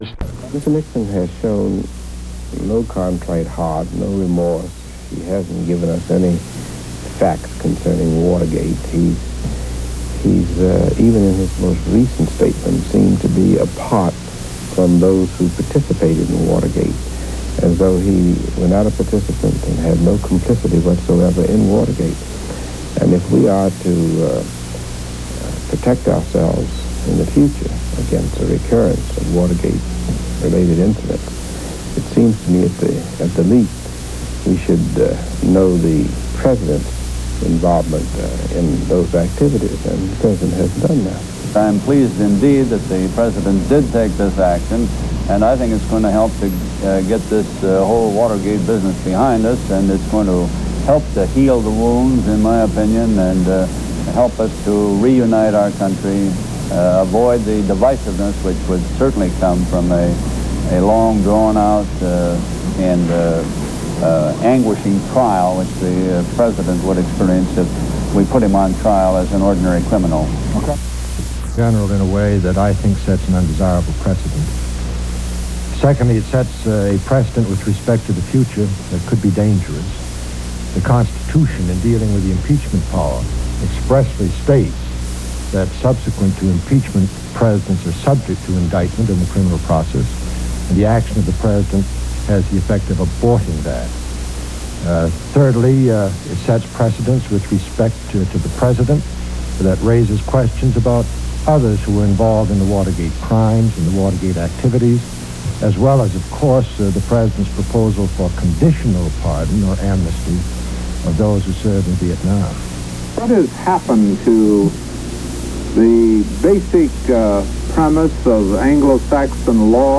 Reaction to President Ford's full pardon of ex-President Nixon by Senators Brooke, Muskie, and Jackson, and Representatives McClory of Illinois and Edwards of California.
Broadcast on CBS-TV, September 9, 1974.